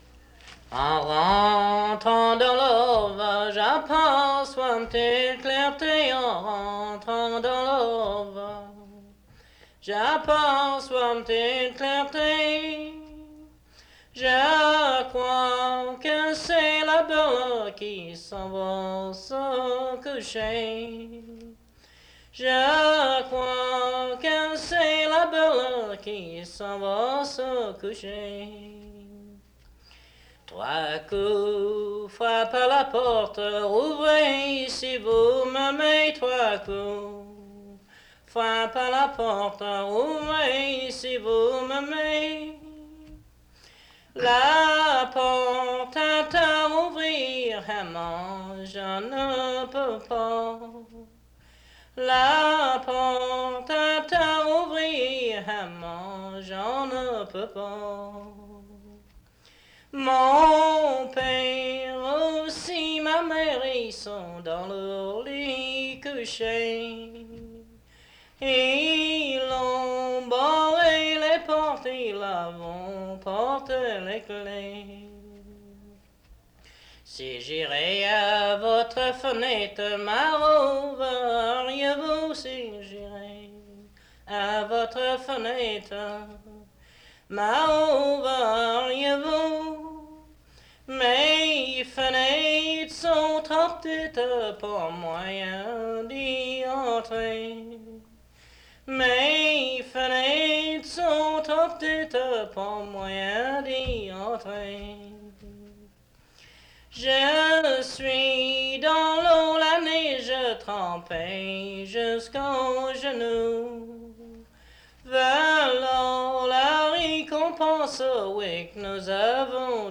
Emplacement Cap St-Georges